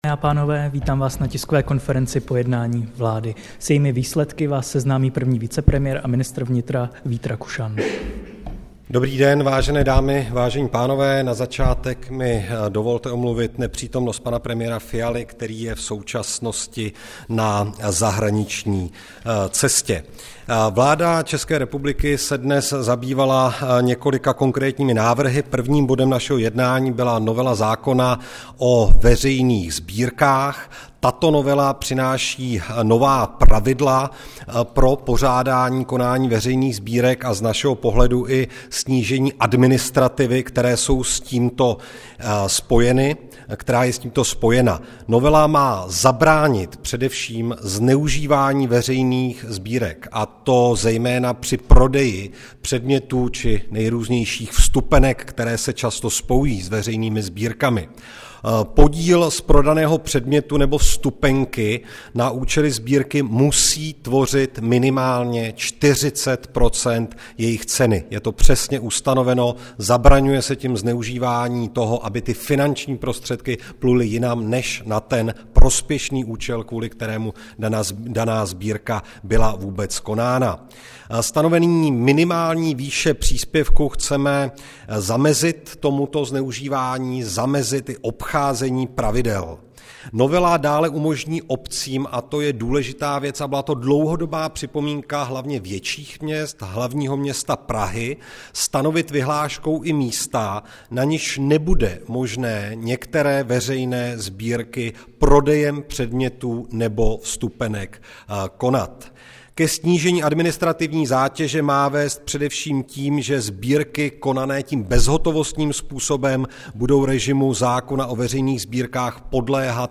Tisková konference po jednání vlády, 10. ledna 2024